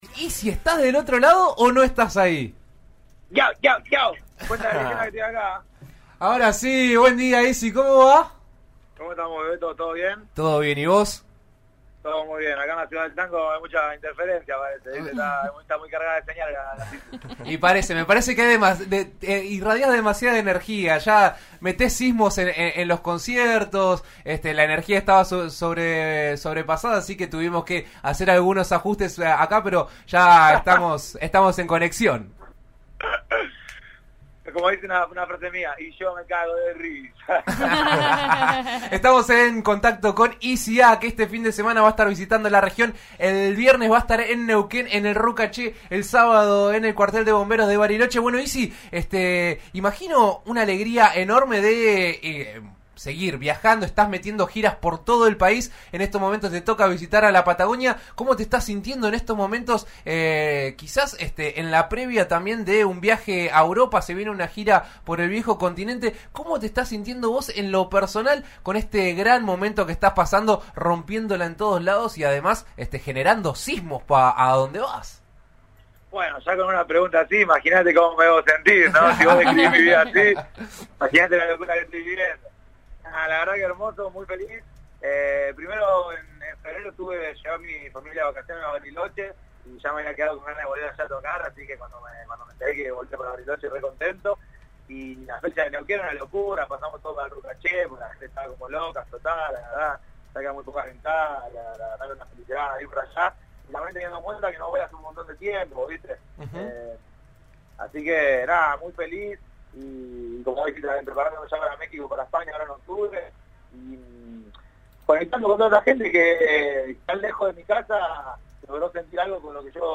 El músico pasará por Neuquén y Bariloche el próximo 2 y 3 de septiembre, y en la previa, habló con En Eso Estamos por RN Radio.
En la previa a sus shows en Neuquén (este viernes 2) y Bariloche (sábado 3), Ysy A pasó por los micrófonos de «En Eso Estamos» de RN RADIO y dejó su característica buena onda, repasando un poco de su carrera y de lo que viene.